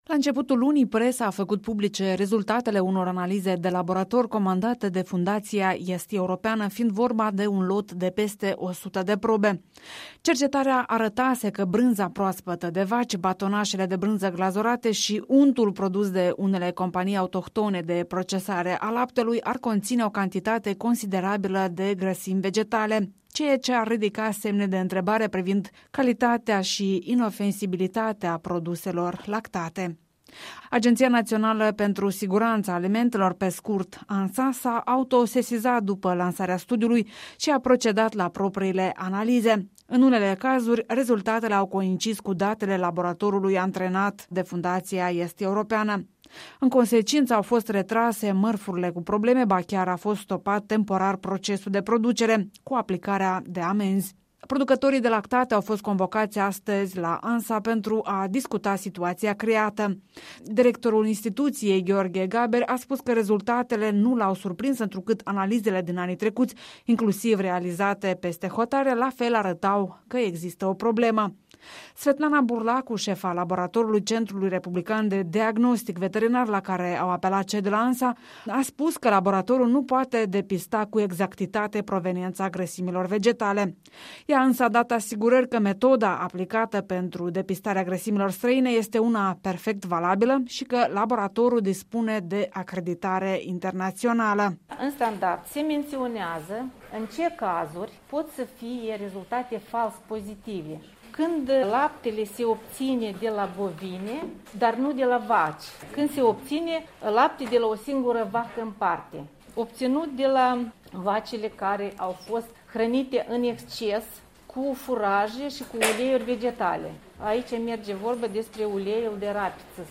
O dezbatere publică la Agenţia Naţională pentru Siguranţa Alimentelor.